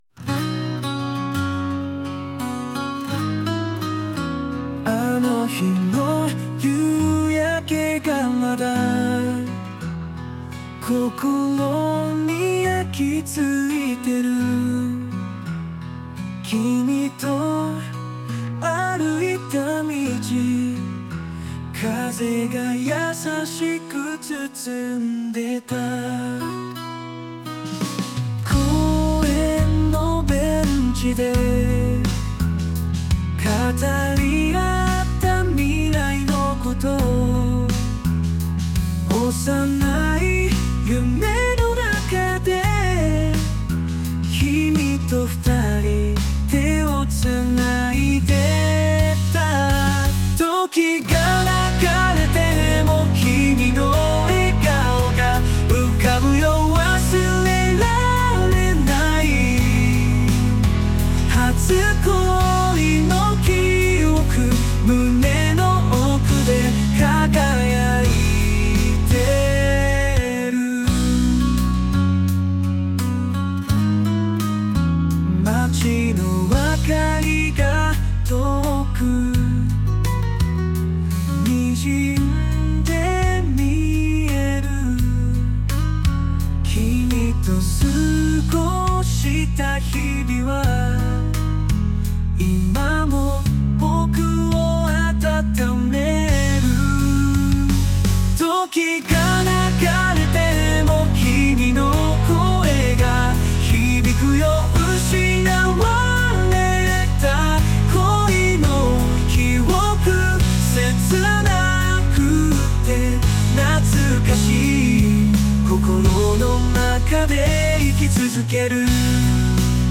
補作詩：GPT-4 Turbo→GPT-4o